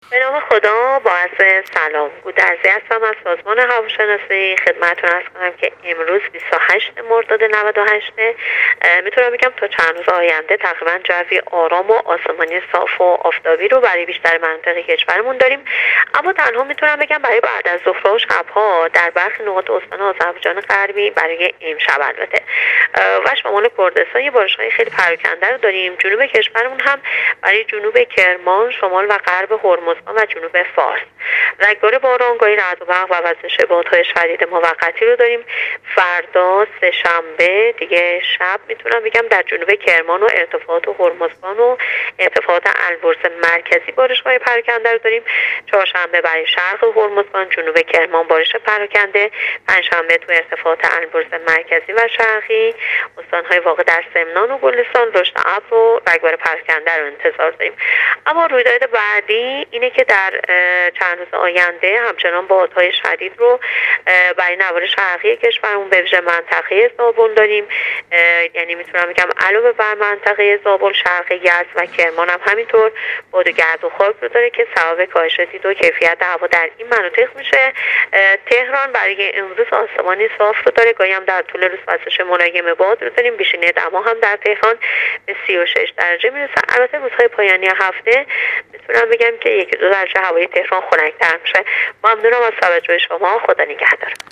گفت‌وگو با رادیو اینترنتی وزارت راه و شهرسازی